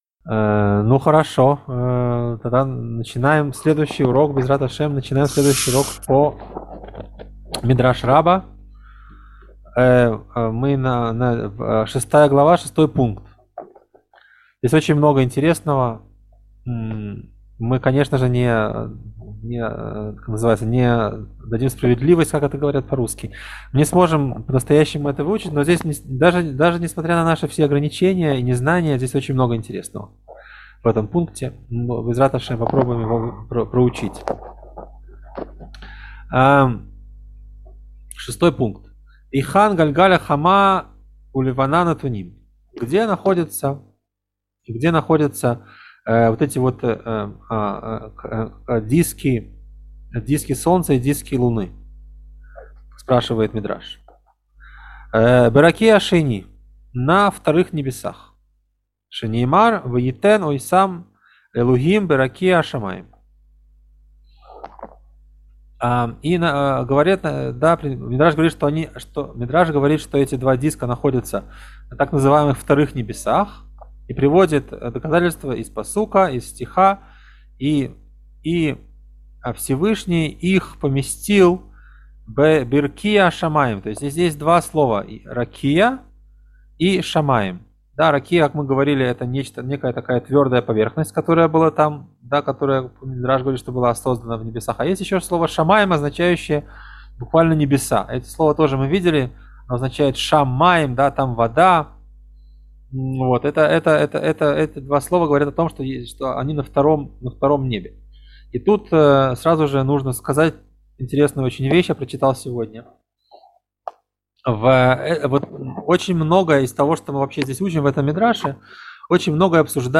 Урок 41